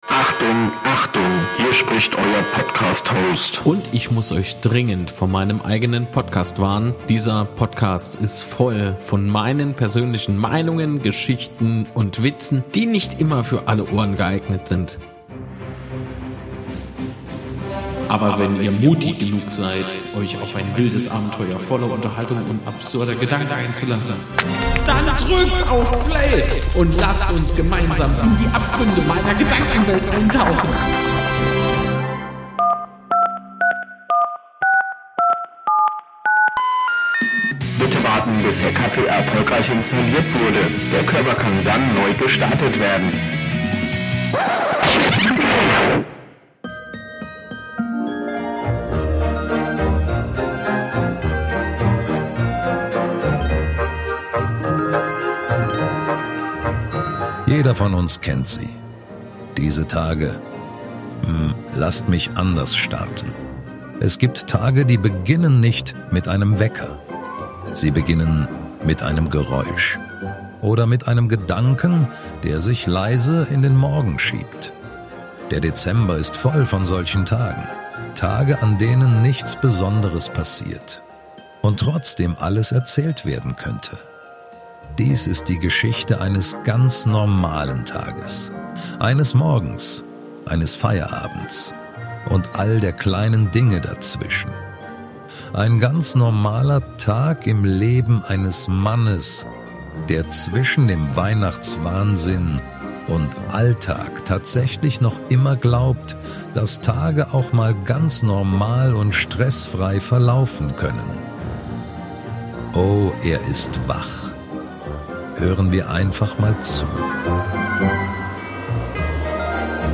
Diese Episode ist kein klassischer Podcast, sondern eine kleine Hörgeschichte. Beobachtend, ruhig und bewusst unspektakulär erzählt.